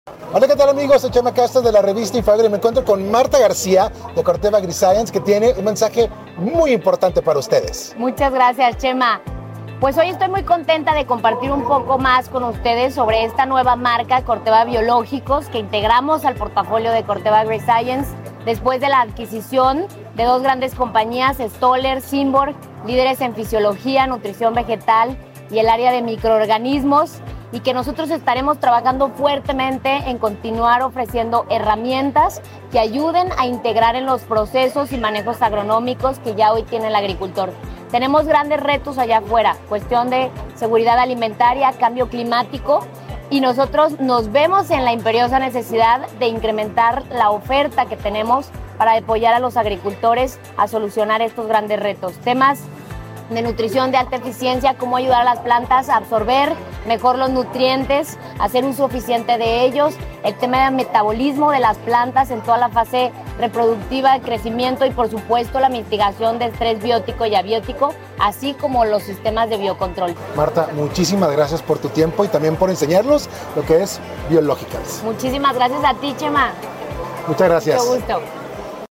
Acompáñanos en esta entrevista con sound effects free download